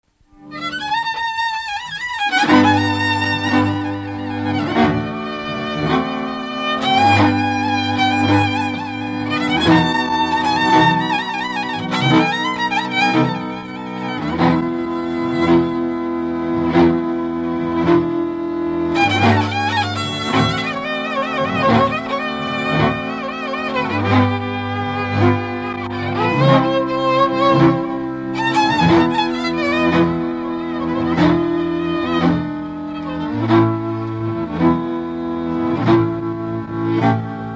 Dallampélda: Hangszeres felvétel
Erdély - Kolozs vm. - Méra
hegedű
brácsa
Műfaj: Csárdás
Stílus: 1.1. Ereszkedő kvintváltó pentaton dallamok